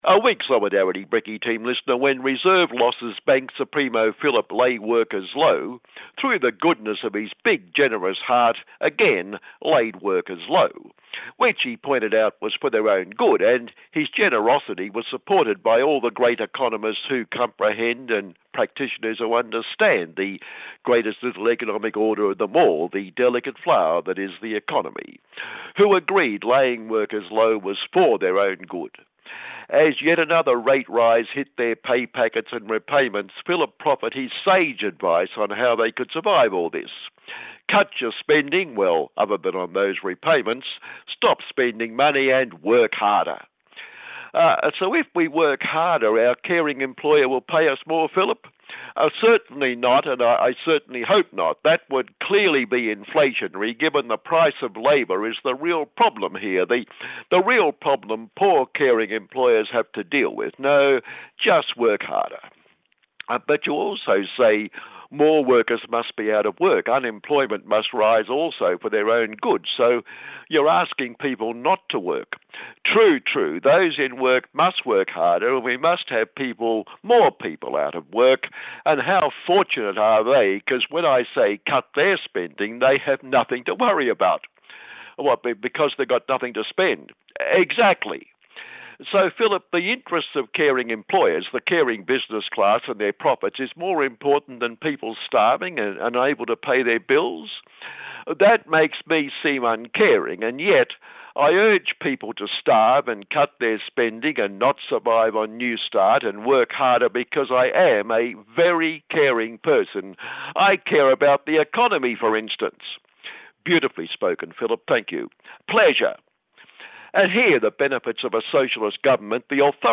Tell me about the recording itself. Mabo Day here II We go to the small group of people at Federation Square in Melbourne to mark Mabo Day 3 June.